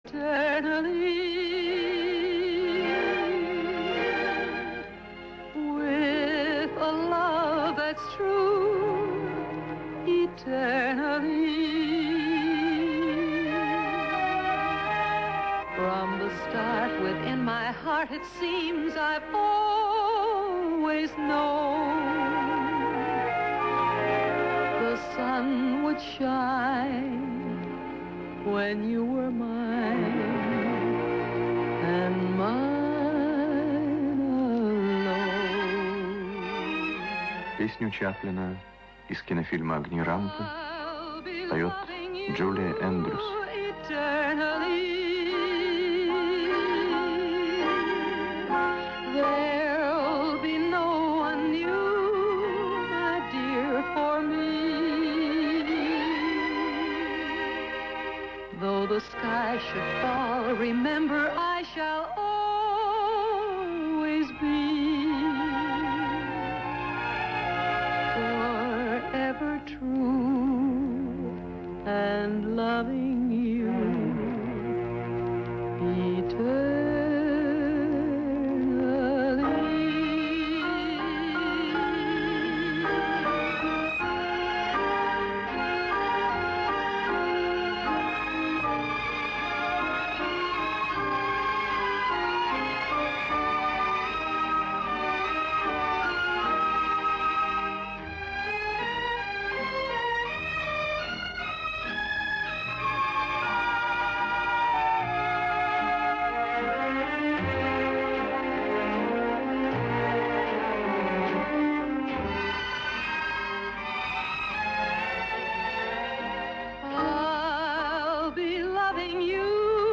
Одна из передач 70-х, где ведущий Виктор Татарский. На 38 секунде он объявляет исполнительницей песни из фильма Чаплина "Огни рампы"  Джулию Эндрюс.